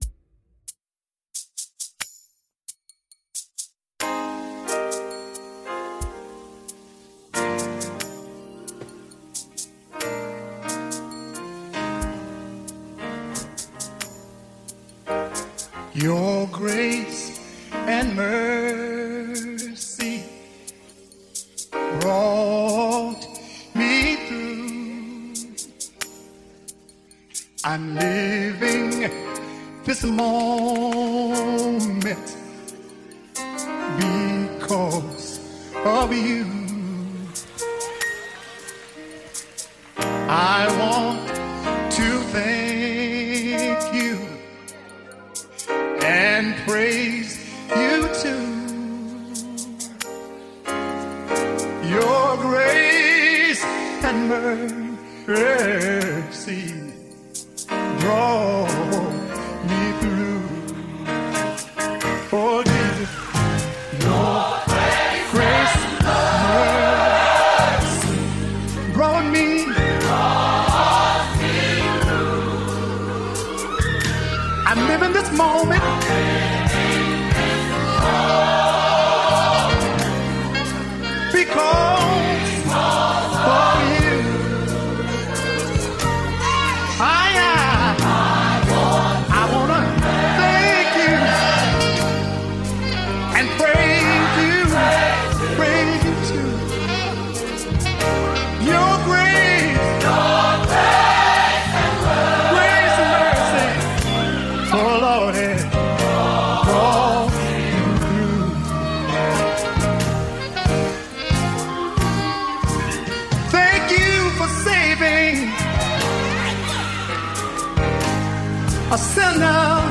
Music Ministry – Women’s Day 2025 – Beth-El Temple Church